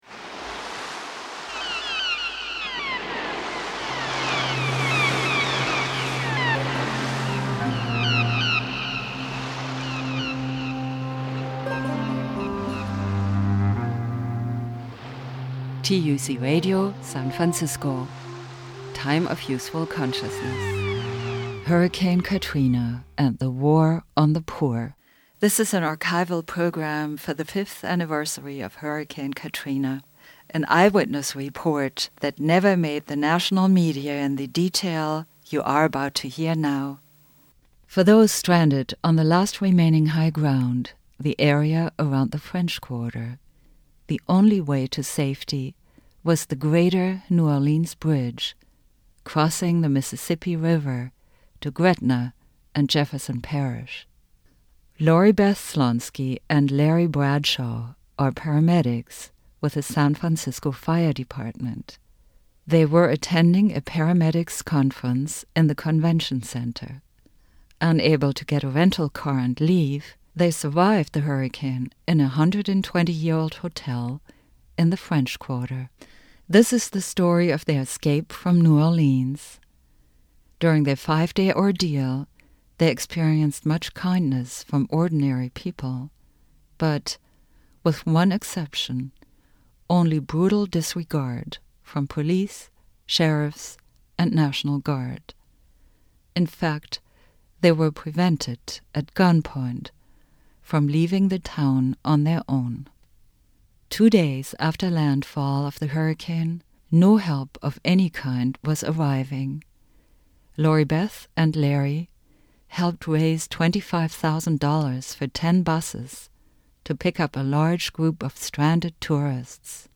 Subtitle: SF Paramedics Prevented, at Gun Point, from Rescuing Themselves and Others Program Type: Weekly Program